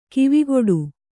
♪ kivigoḍu